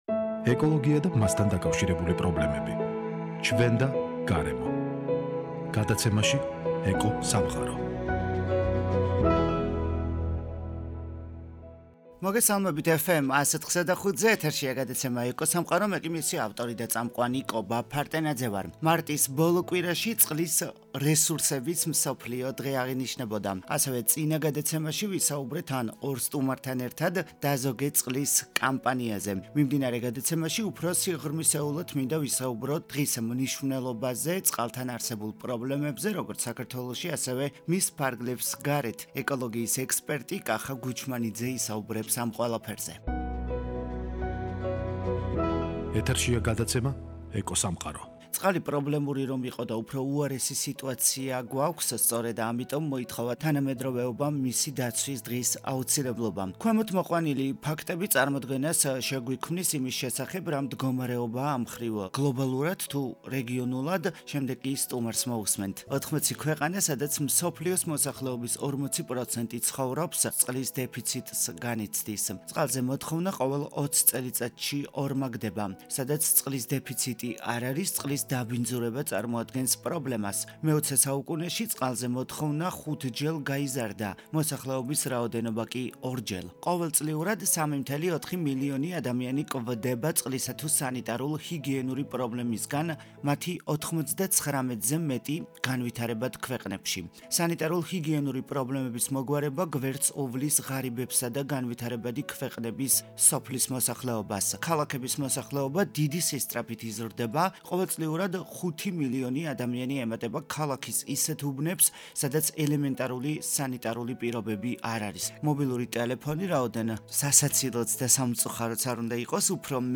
სატელეფონო ჩართვით